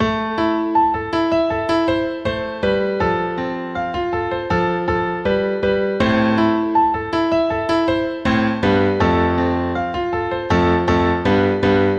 带有重音的低音符的钢琴里夫
描述：正如描述的那样。只是一些基本的琶音，从A大调开始用降大调演奏。
Tag: 80 bpm Pop Loops Piano Loops 2.02 MB wav Key : Unknown